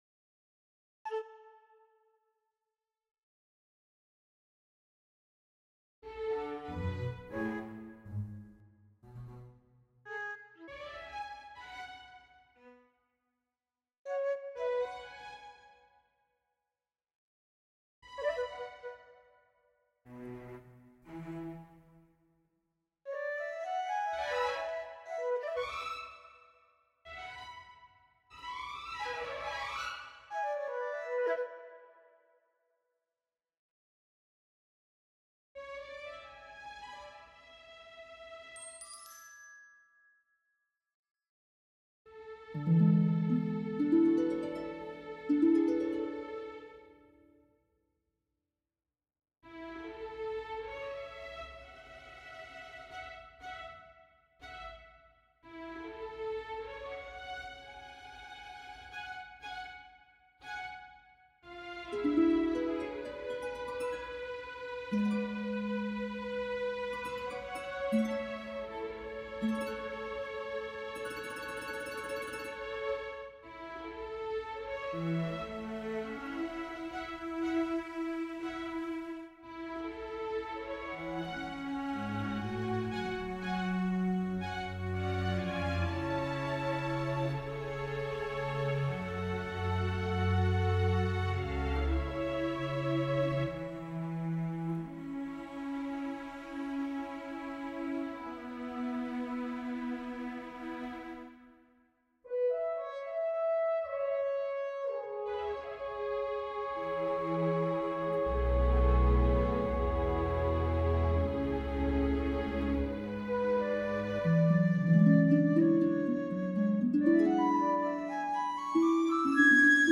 A Symphonic Composition for Harp, Symphony Orchestra, Voice Solo and Children’s Choir
It is a lyrical and vivid composition for full symphony orchestra and children’s choir. Along the way, the music intertwines traditional elements of the Austrian waltz with more modern and experimental approaches to composition.